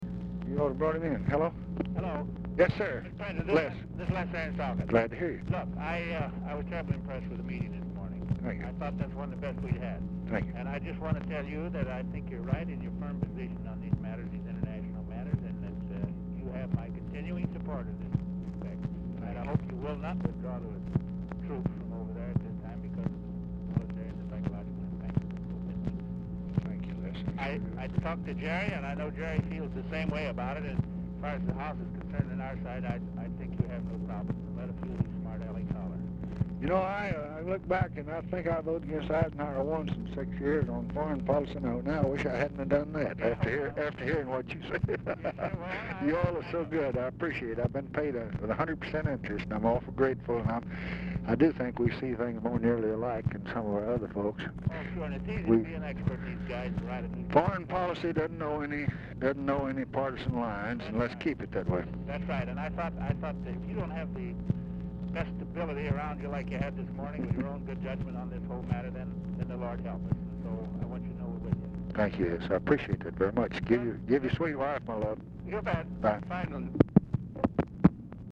Telephone conversation # 11566, sound recording, LBJ and LESLIE ARENDS, 2/27/1967, 4:20PM
Dictation belt